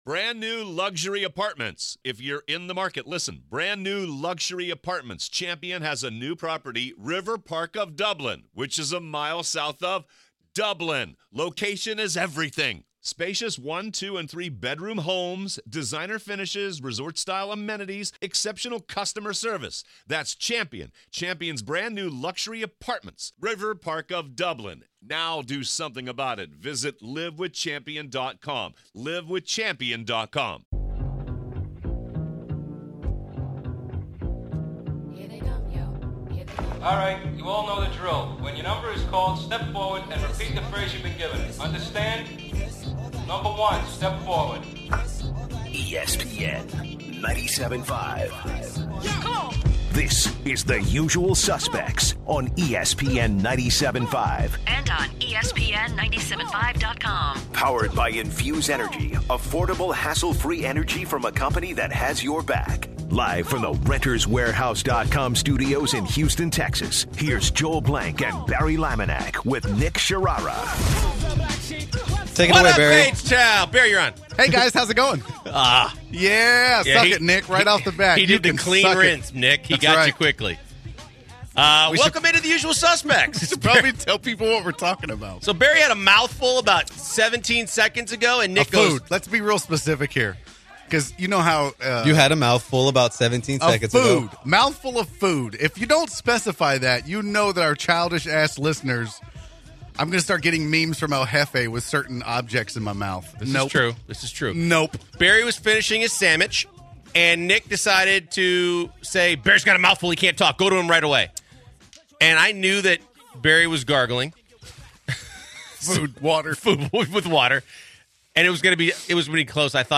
On the first hour the guys talk Houston Rockets after their devastating loss to the spurs, they talk about how James Harden essentially disappeared and was completely ineffective. MLB insider Buster Olney also joins the program to talk baseball and as he does every week NBA all star and former rockets coach Kevin McHale joins the program as well.